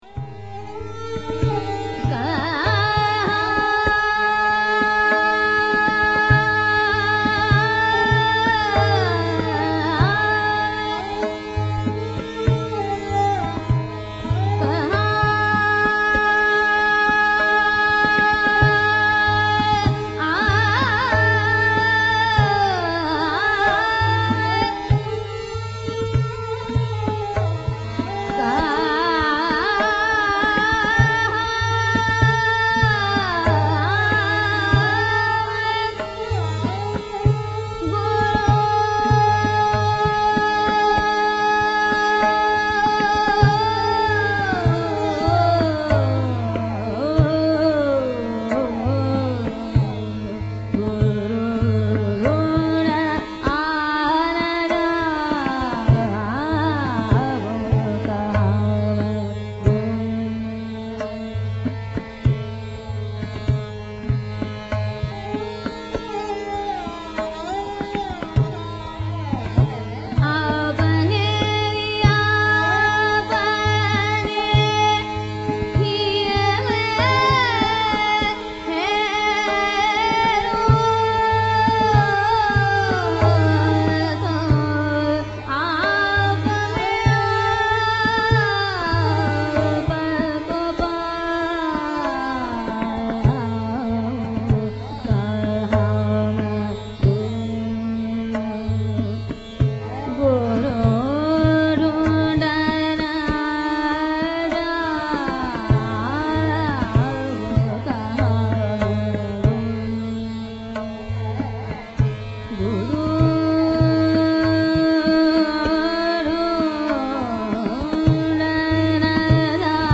Raga Shree: Close Encounters
Shruti Sadolikar‘s version reveals a variation on the bandish.